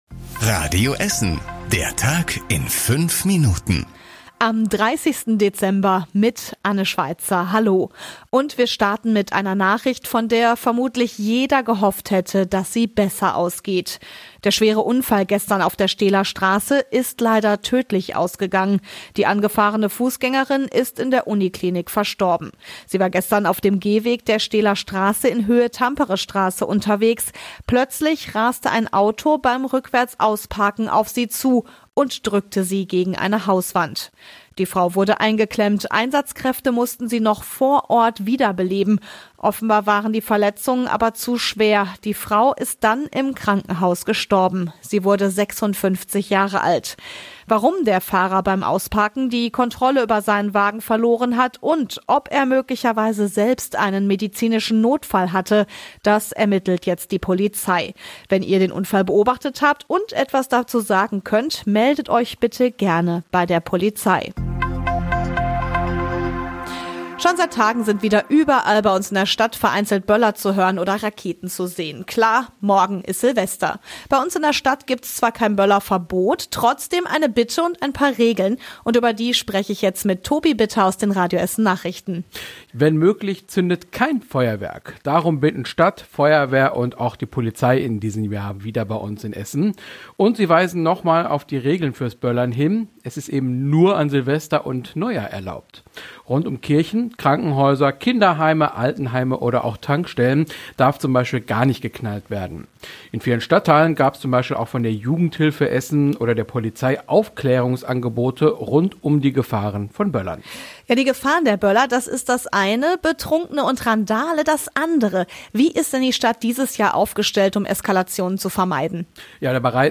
Die wichtigsten Nachrichten des Tages in der Zusammenfassung
Nachrichten